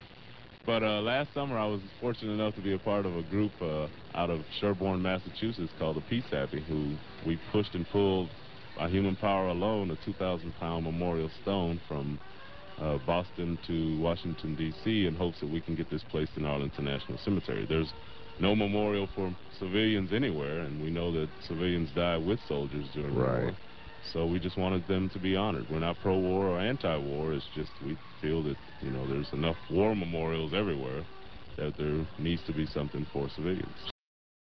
at KYJT radio Yuma, AZ